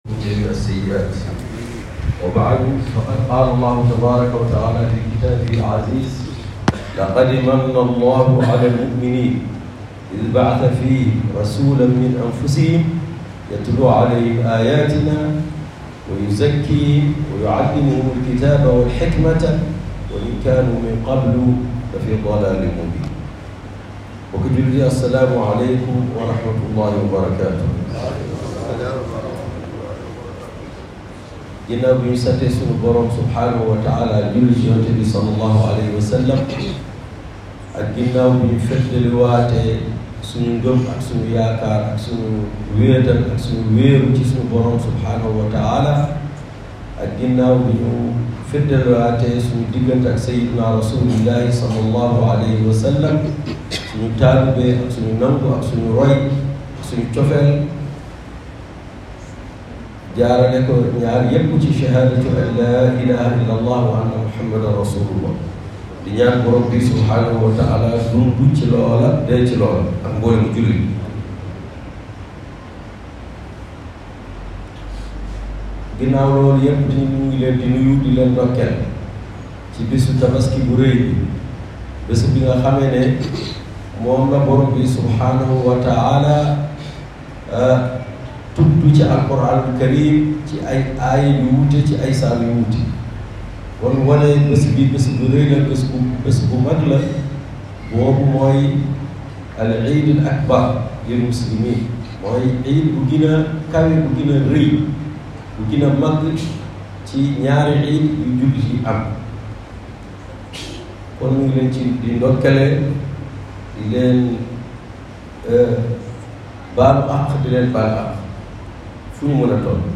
Tabaski 2023 - Le sermon